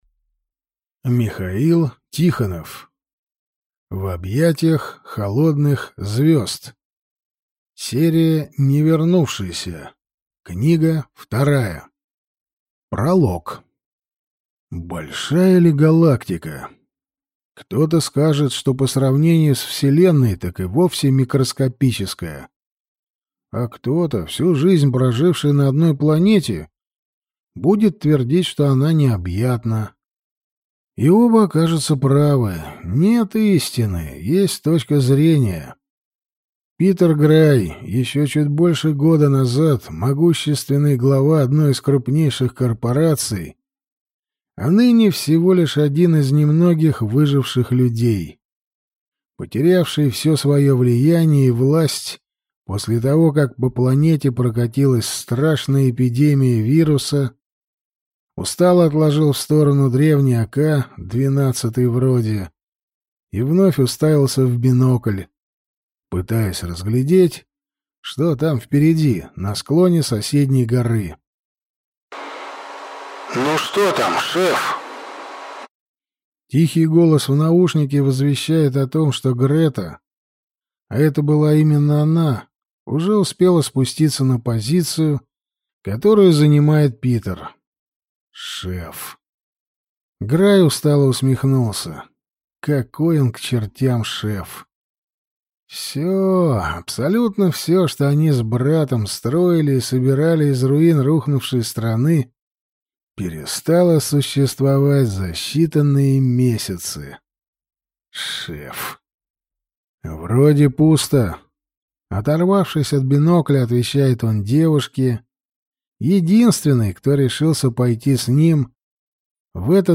Аудиокнига В объятиях холодных звезд | Библиотека аудиокниг